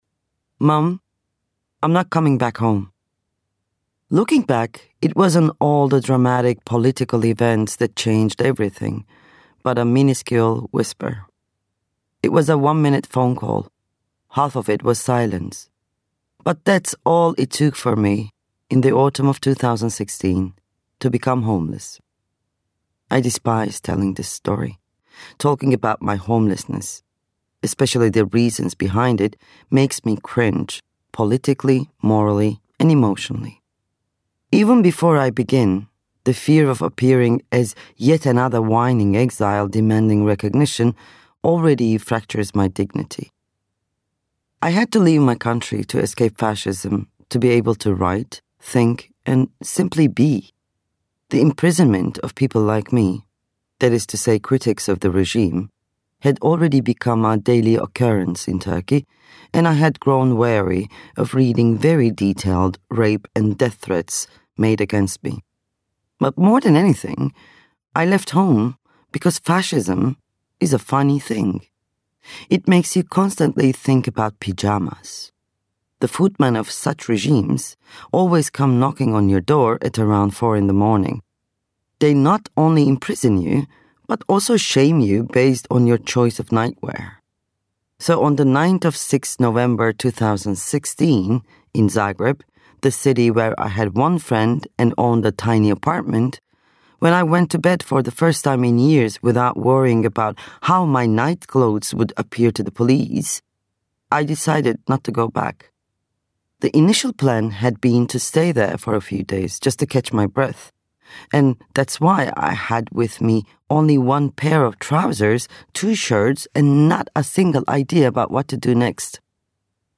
Audiobook sample